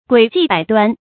鬼計百端 注音： ㄍㄨㄟˇ ㄐㄧˋ ㄅㄞˇ ㄉㄨㄢ 讀音讀法： 意思解釋： 見「鬼計多端」。